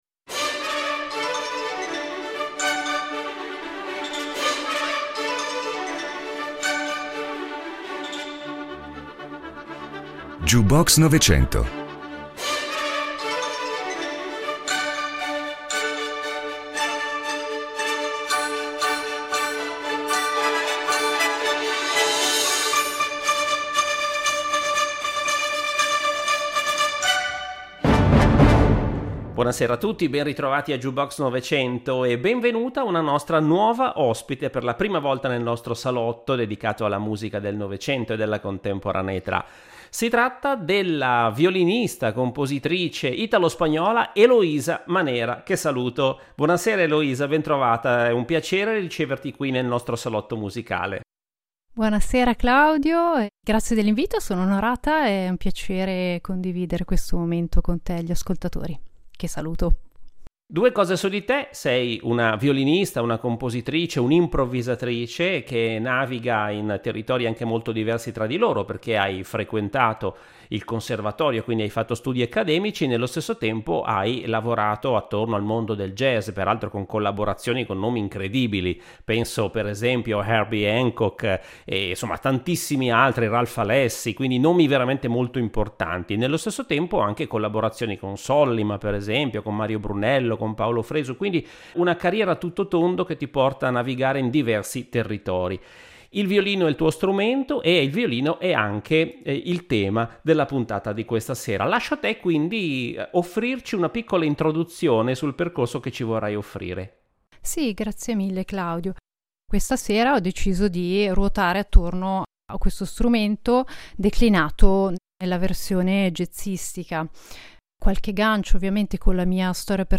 La scena attuale è popolata da musicisti straordinari che fanno tesoro, ognuno a proprio modo, della tradizione che li ha preceduti, creando paesaggi sonori personali e spesso trasversali. La musica classica antica e contemporanea si mescolano con la tradizione ebraica, le note elettriche della fusion e la ricerca microtonale, si confrontano con le radici del folk d’oltreoceano.